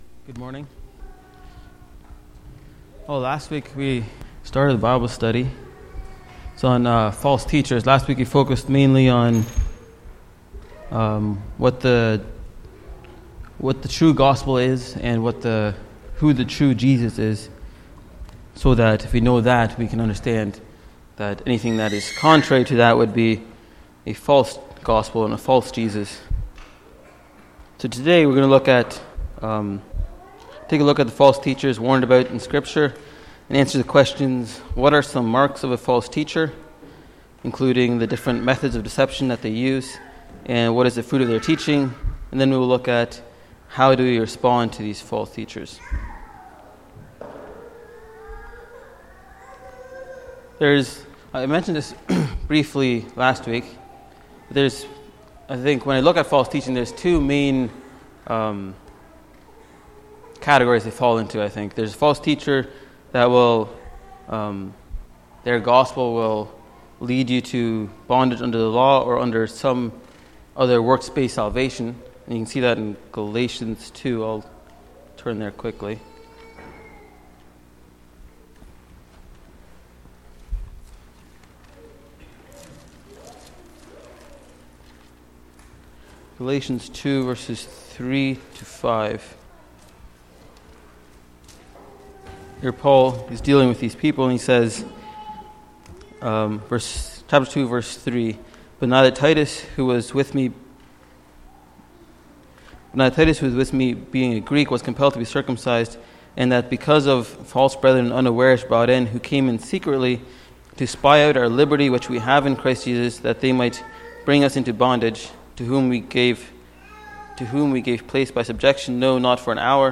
Sunday Morning Bible Study Service Type: Sunday Morning